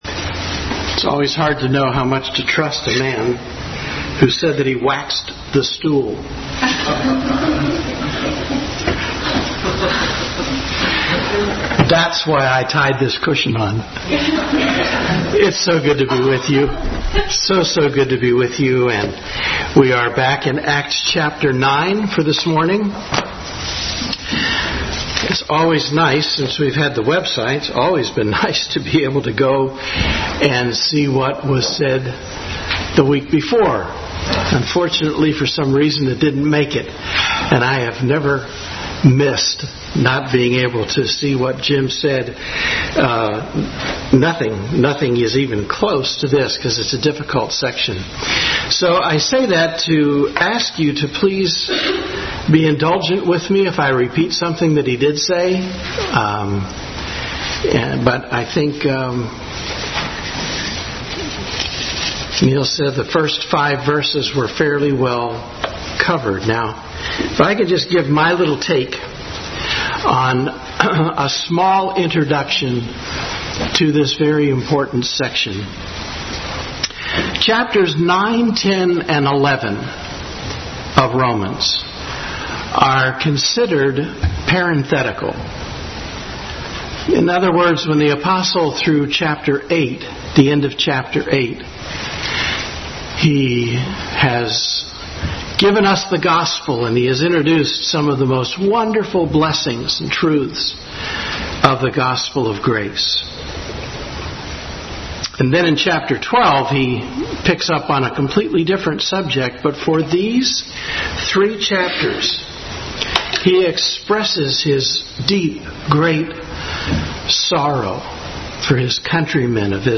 Acts 9:4-16 Service Type: Sunday School Bible Text